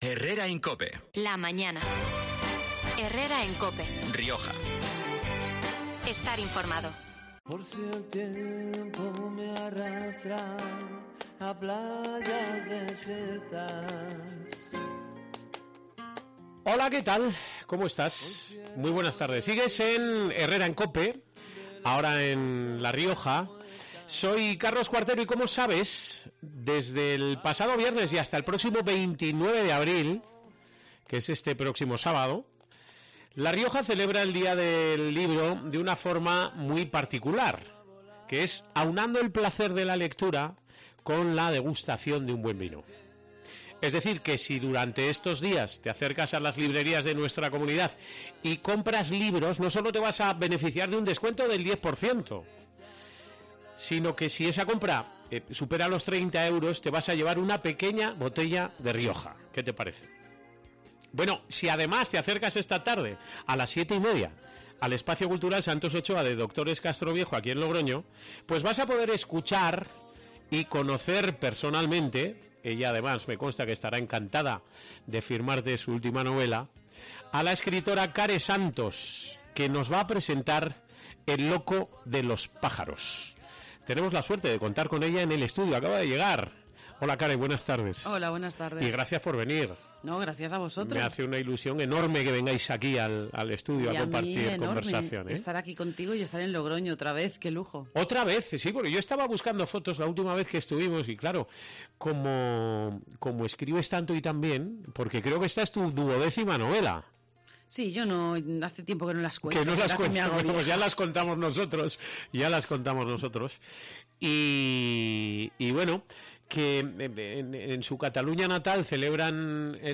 La autora ha pasado este miércoles por los micrófonos de COPE Rioja para aplaudir el buen momento por el que atraviesa el sector editorial